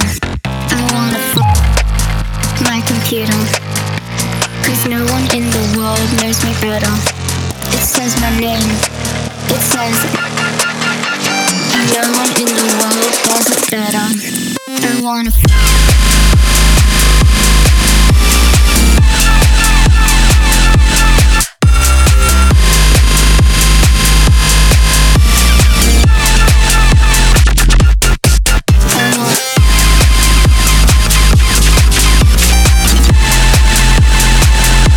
2025-05-14 Жанр: Танцевальные Длительность